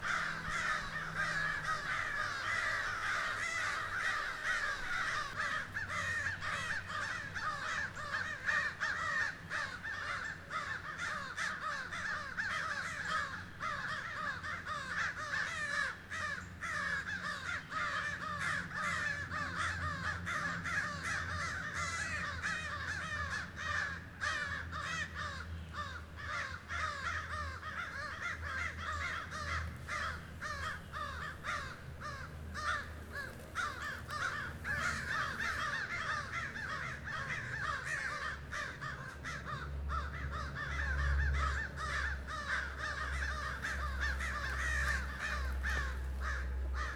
sound_library / animals / crows